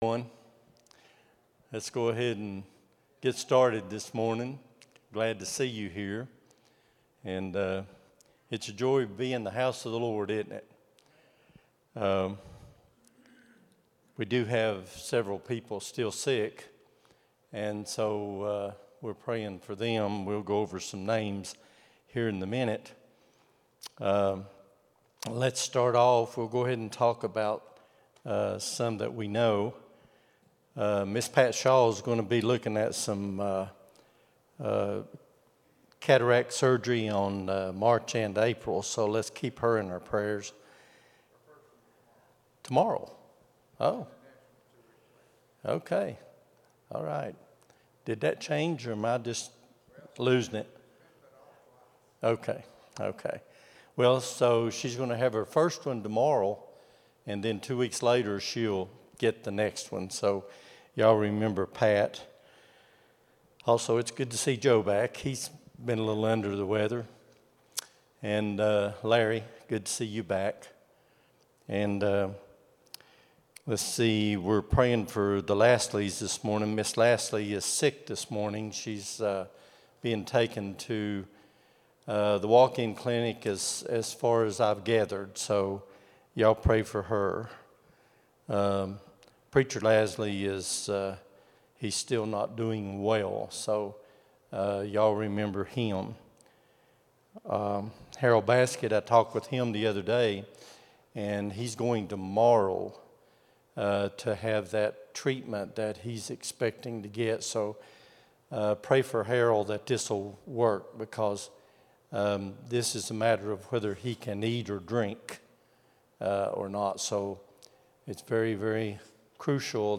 02-23-25 Sunday School | Buffalo Ridge Baptist Church